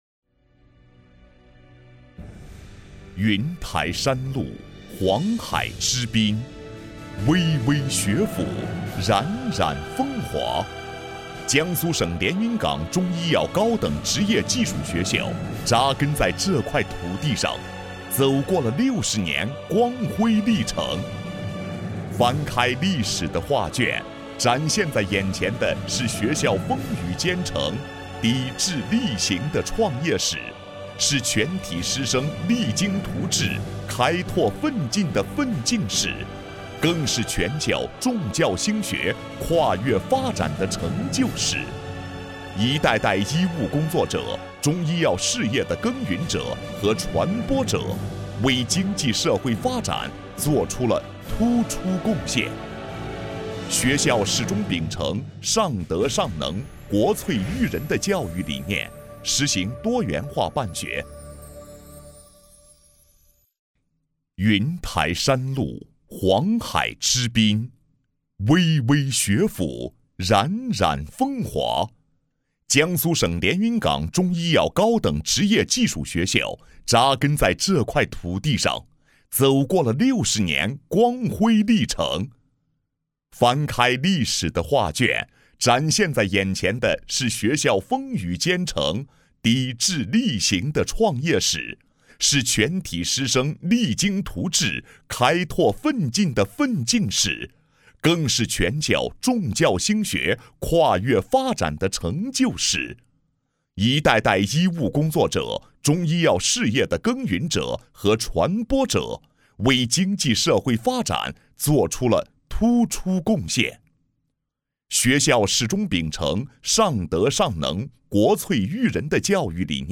男国101_专题_学校_中医药高等职业学校_大气.mp3